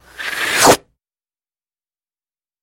На этой странице собраны звуки черной дыры, преобразованные из электромагнитных и гравитационных волн.
Звук черной дыры, поглощающей все ненужное (например, твой диплом)